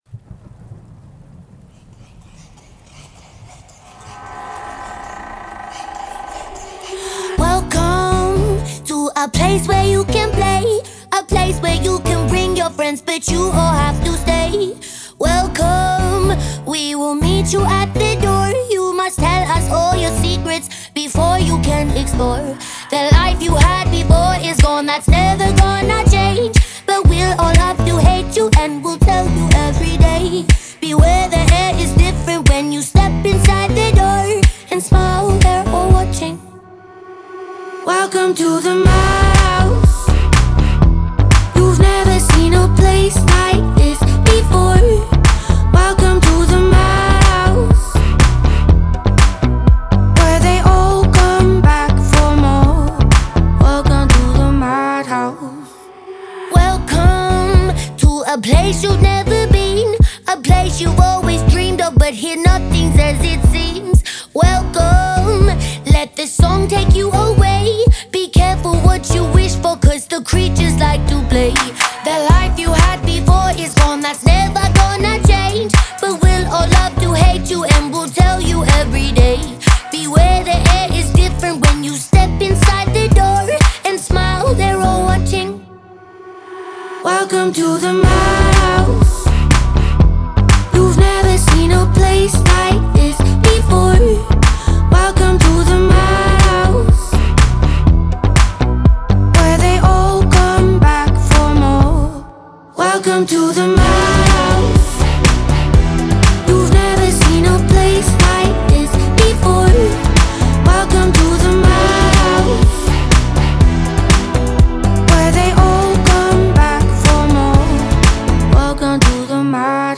это динамичная поп-песня австралийской певицы